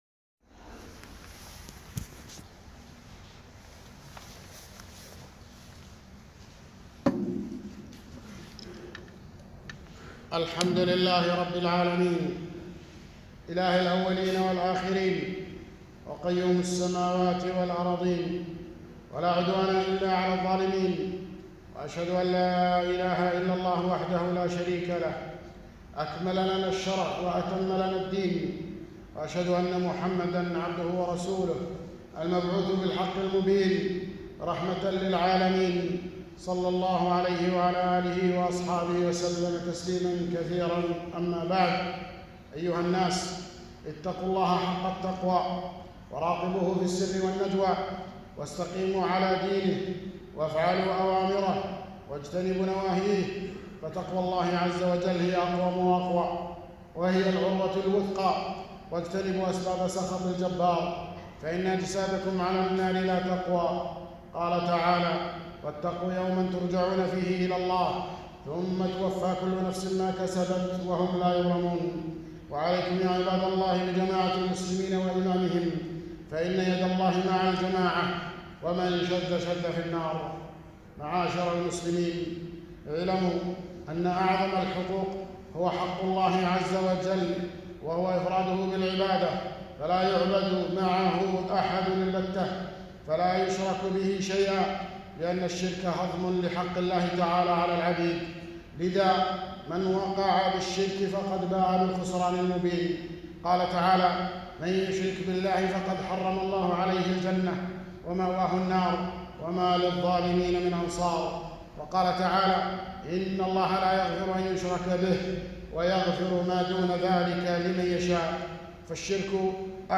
خطبة - من الشرك لبس الحلقة والخيوط والأساور لرفع البلاء